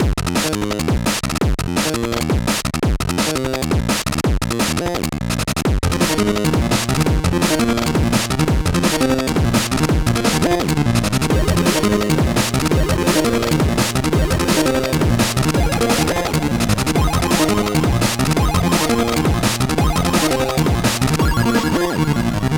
030_Funkmetal dnb WEEK 1 GB sample.wav